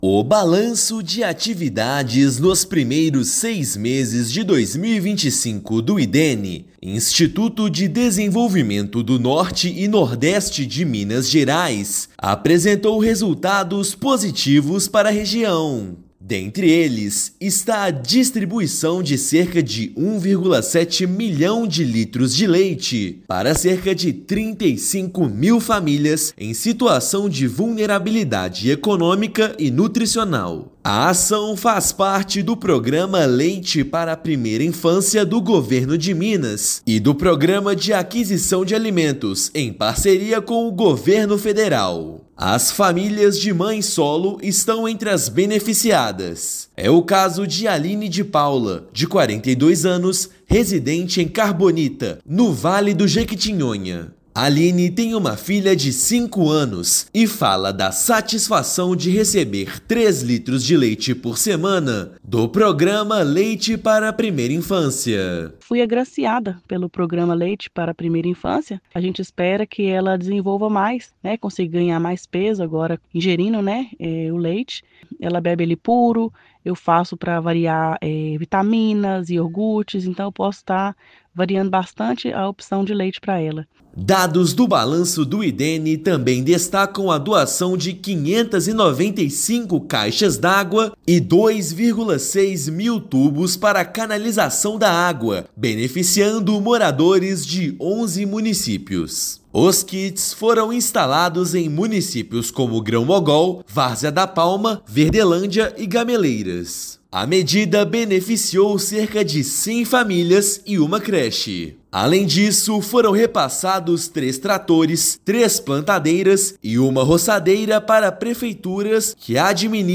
Distribuição de 1,7 milhão de litros de leite, doação de 595 caixas d’água e instalação de kits fotovoltaicos em quatro municípios são alguns dos resultados. Ouça matéria de rádio.